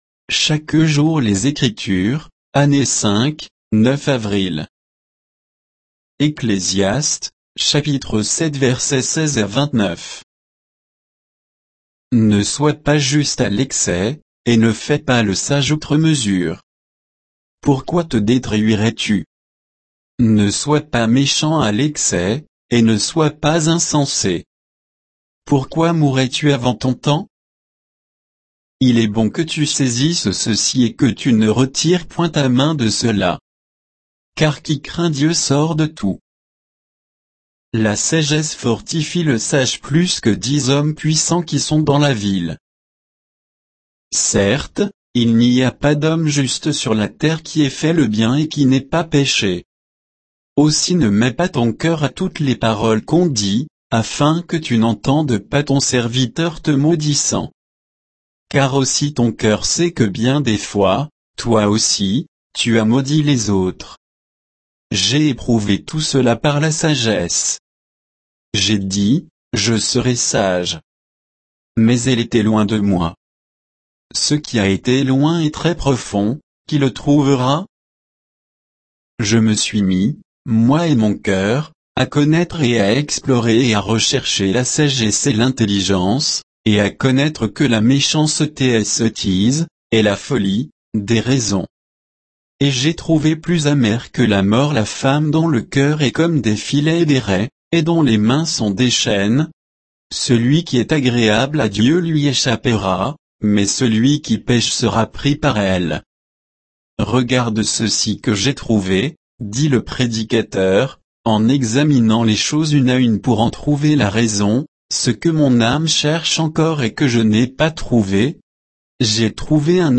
Méditation quoditienne de Chaque jour les Écritures sur Ecclésiaste 7, 16 à 29